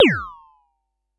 Vermona Perc 09.wav